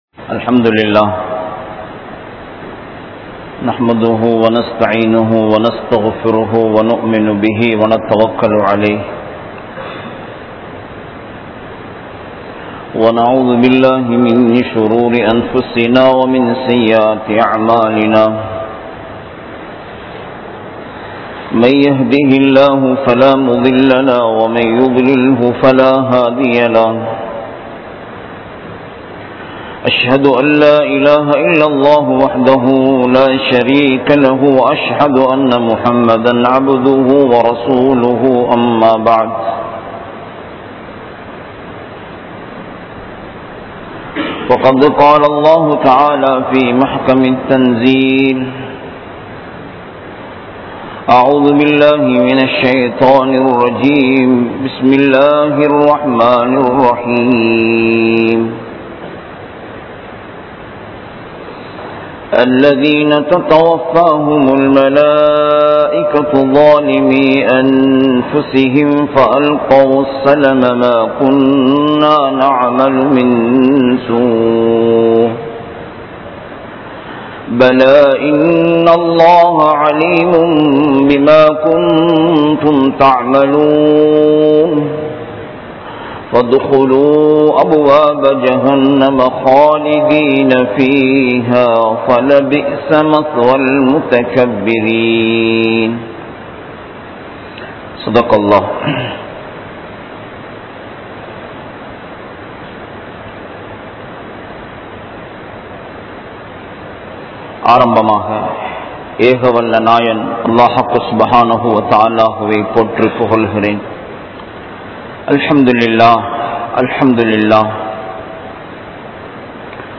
Allah`vukku Neengal Nantri Ullavara? (அல்லாஹ்வுக்கு நீங்கள் நன்றி உள்ளவரா??) | Audio Bayans | All Ceylon Muslim Youth Community | Addalaichenai
Meera Masjith(Therupalli)